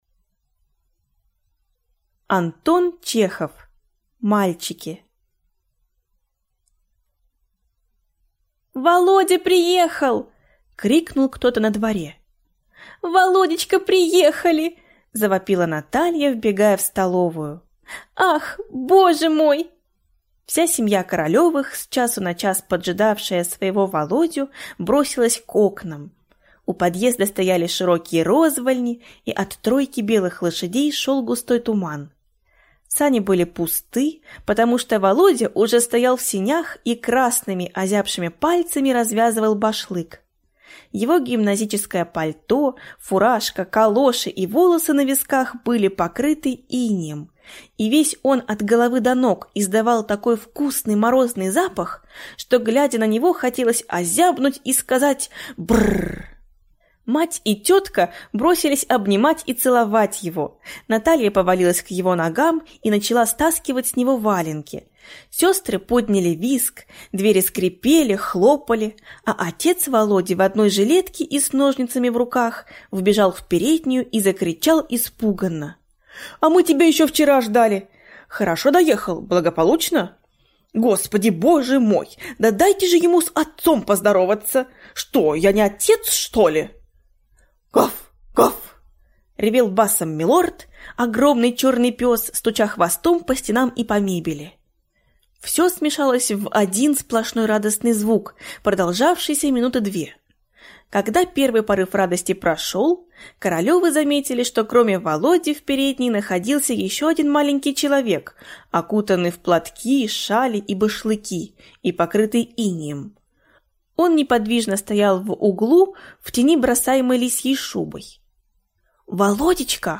Аудиокнига Мальчики | Библиотека аудиокниг